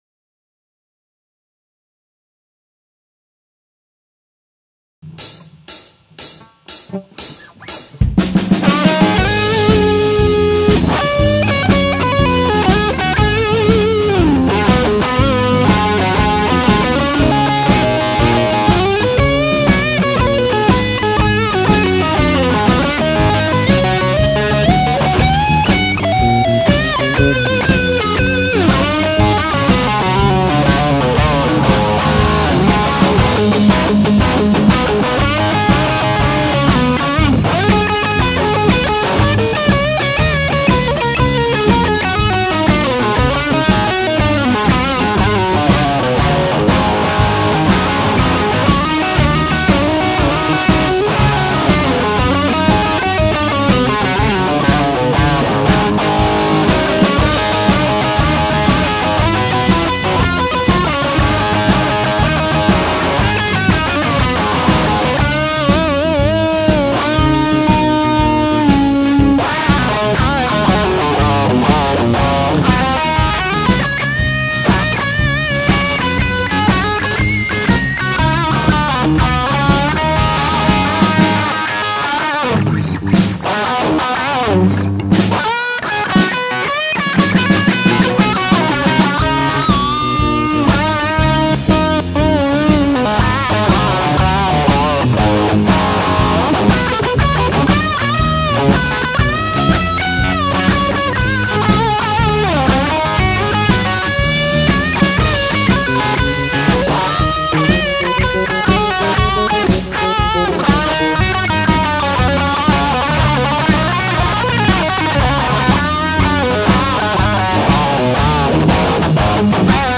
Guitar blues performance